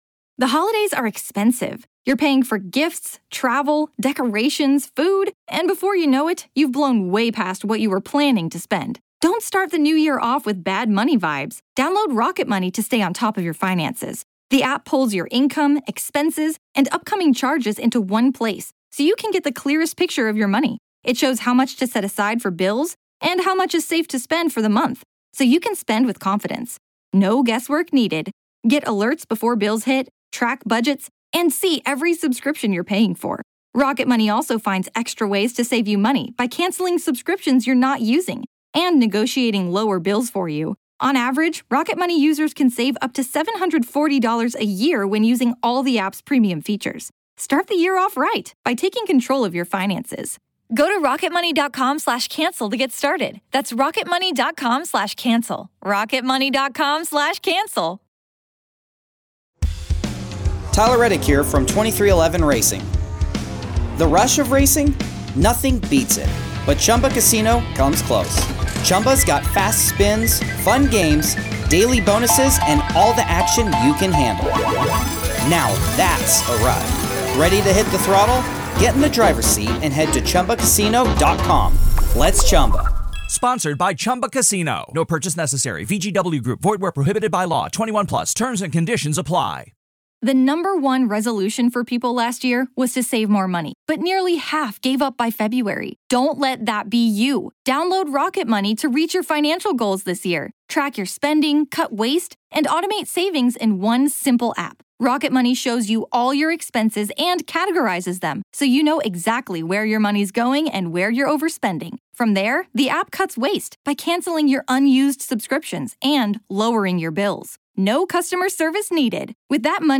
The duo dives deep into the recent Homeland Security raids on Sean "Puffy" Combs' properties, dissecting the ins and outs of a case that's as convoluted as it is sensational. Here’s the skinny on their chat: Collaborative Efforts: It's all hands on deck with federal agencies joining forces in a grand display of bureaucracy at its finest.